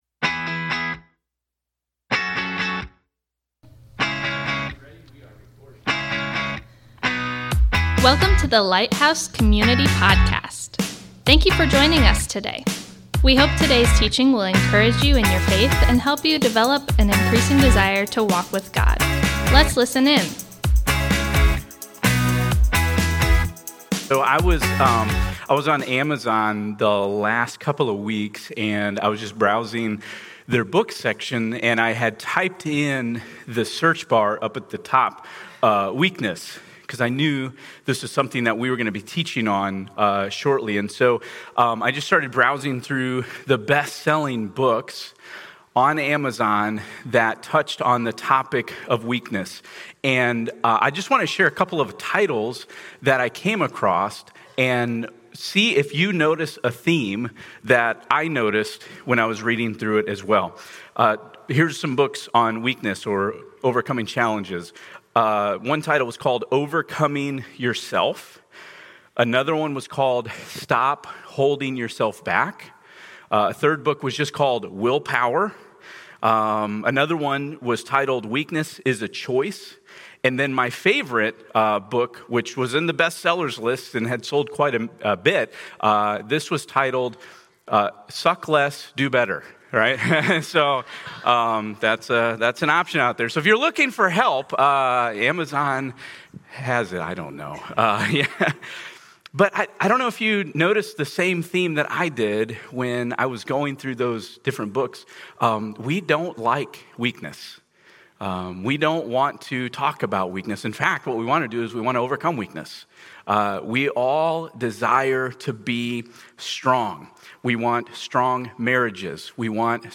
Thank you for joining us today as we come together to worship the Lord!